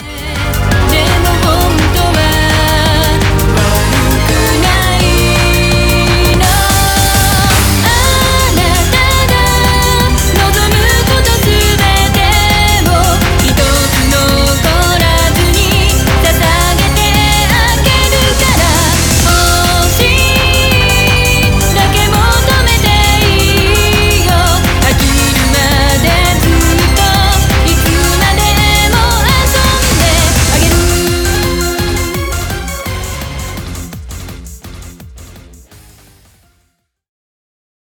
TRANCE